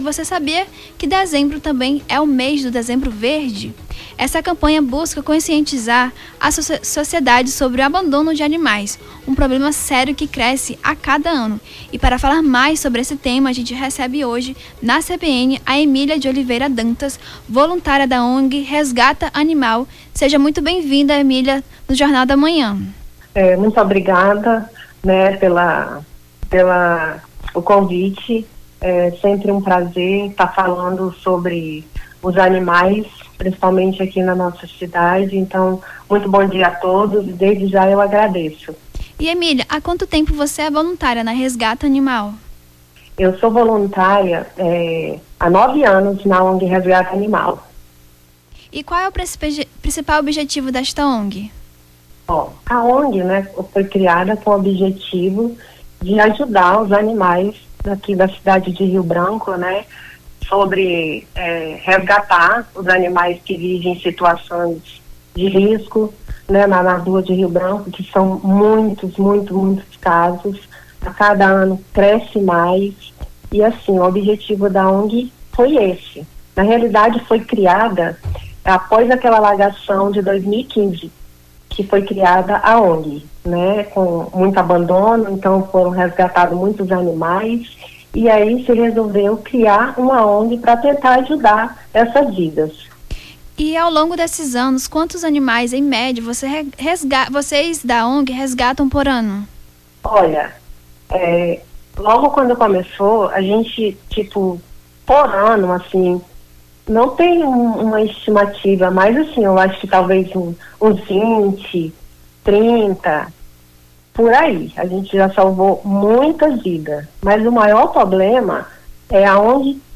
Nome do Artista - CENSURA - ENTREVISTA DEZEMBRO VERDE (05-12-24).mp3